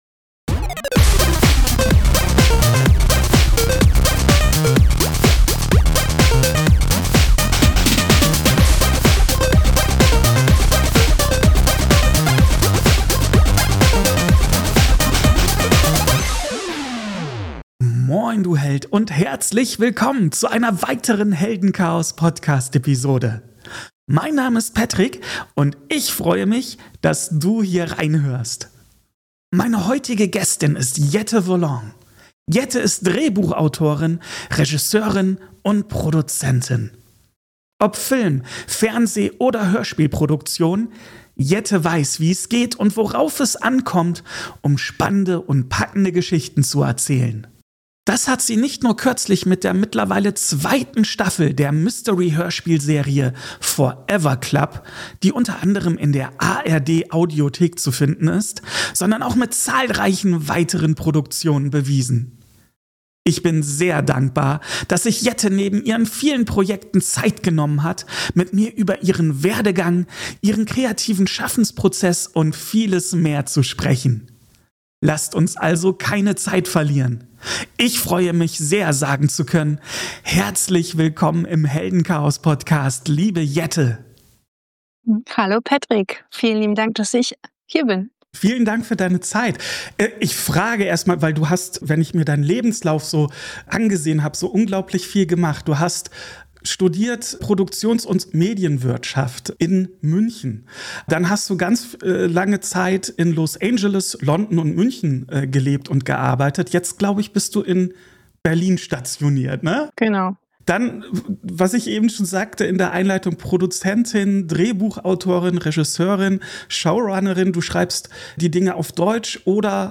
Im Gespräch mit Autorin